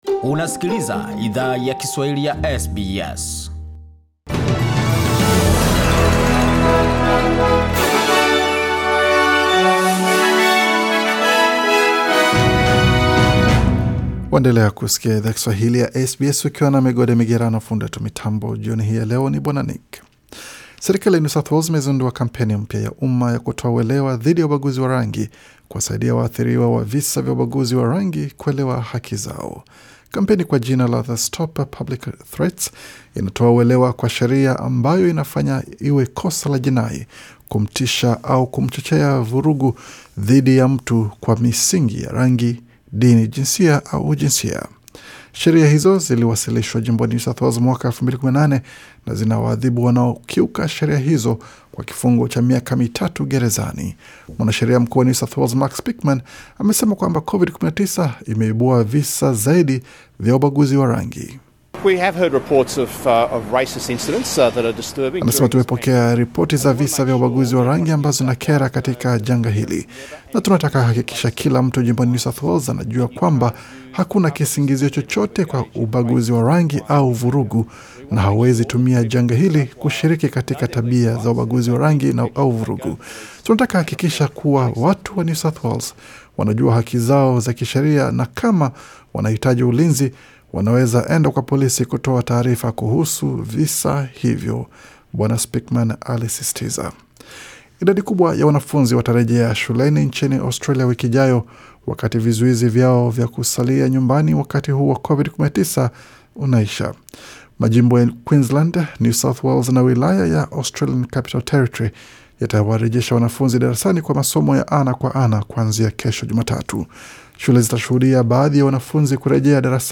Taarifa ya habari 28 Juni 2020